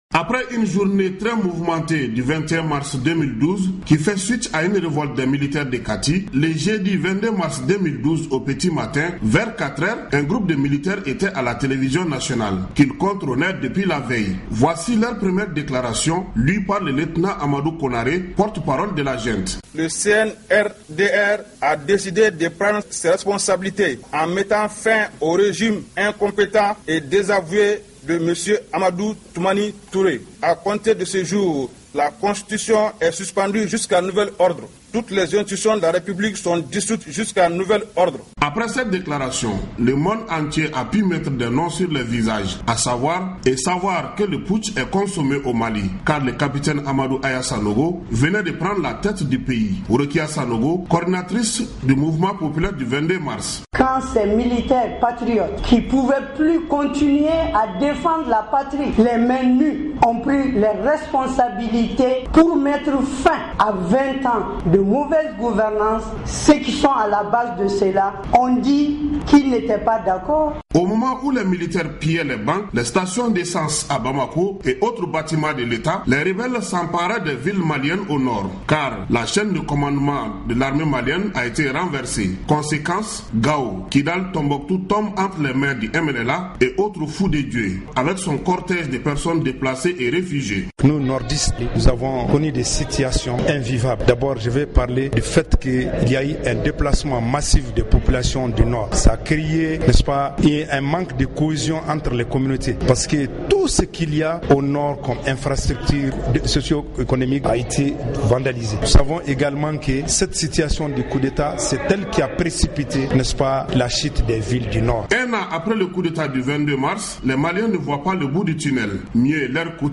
Les réactions au Mali avec notre correspondant